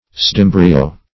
Search Result for " pseudembryo" : The Collaborative International Dictionary of English v.0.48: Pseudembryo \Pseu*dem"bry*o\, n. [Pseudo- + embryo.]